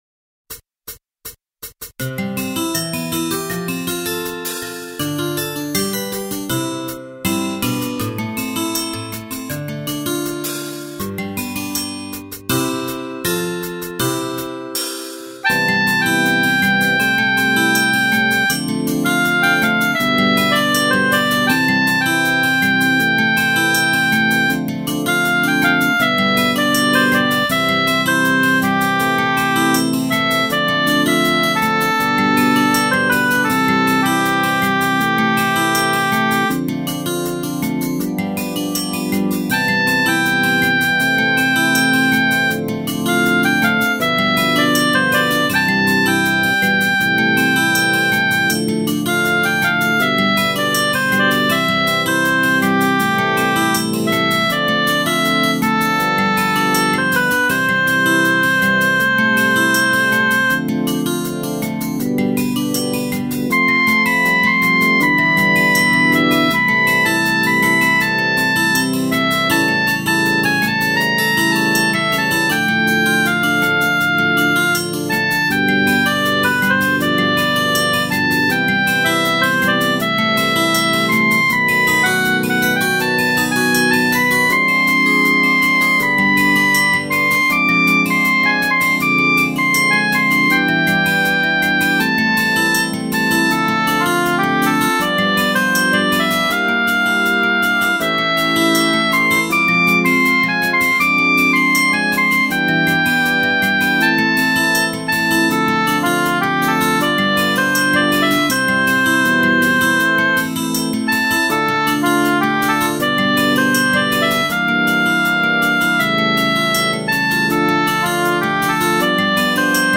2982   03:59:00   Faixa: 9    Clássica